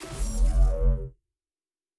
Shield Device 3 Start.wav